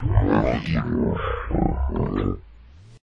随机噪音 " 深度咆哮
描述：深深的咆哮和笑声。在大声呼吸麦克风后，用Audule过滤器制作Audacity过滤器。
标签： 恐怖 愤怒 大笑 外星人 怪物 咆哮声 呻吟
声道立体声